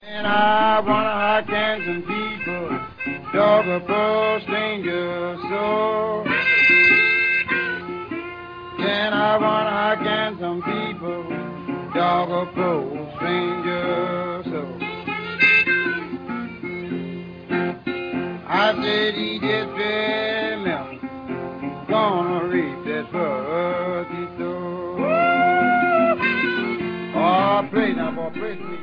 вокал, гитара
губная гармоника